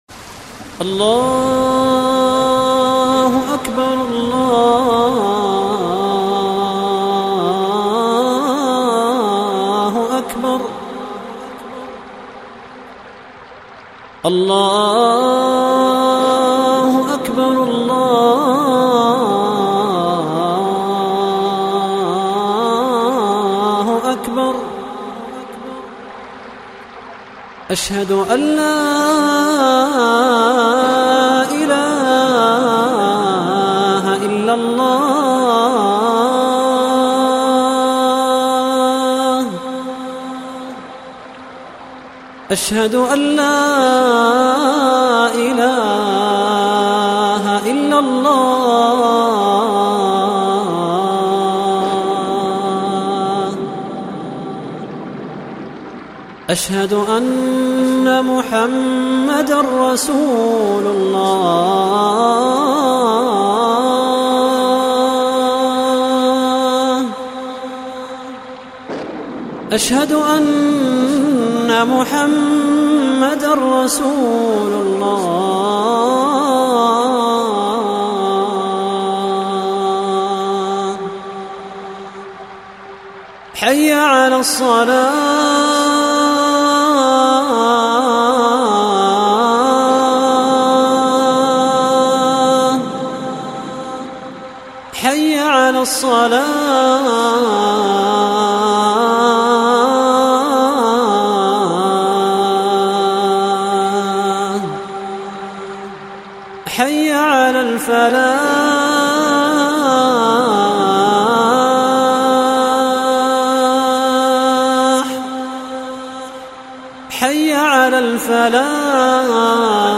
آذان
athan7.mp3